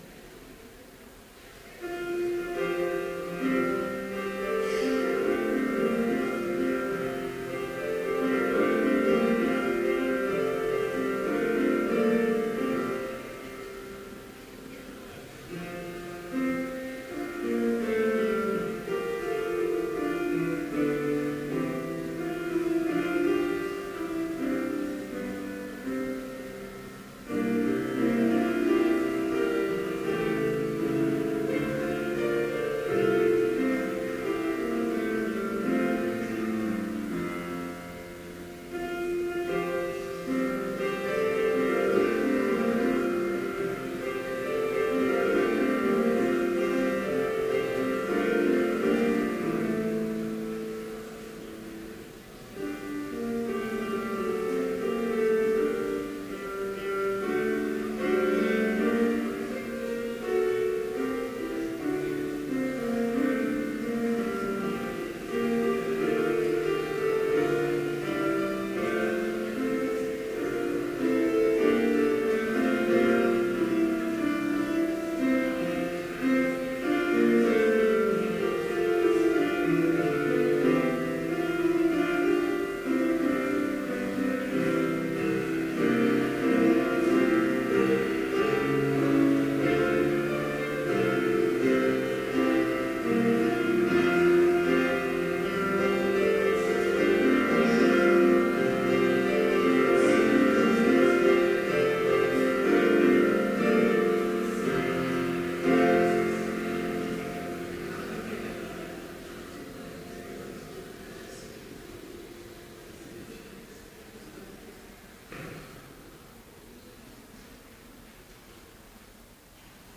Complete service audio for Chapel - April 17, 2015